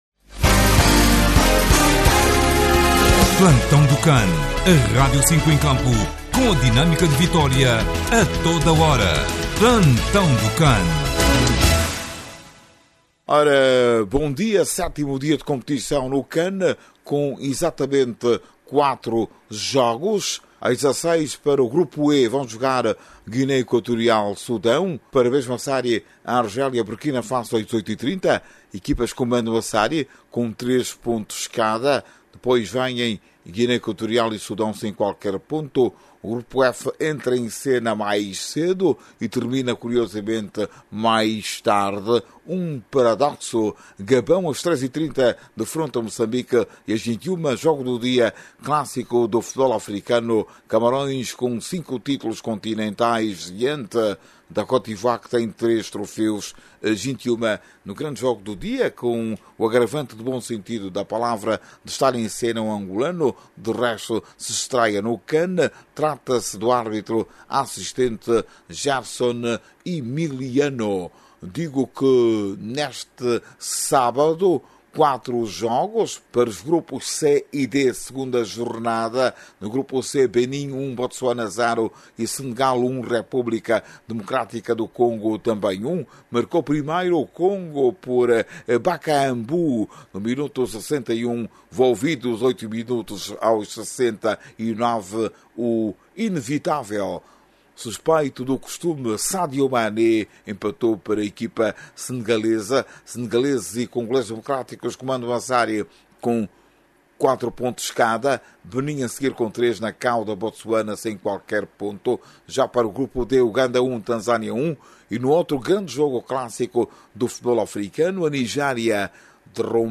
PLANTAO-DO-CAN.mp3